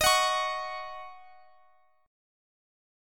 Listen to D#m7 strummed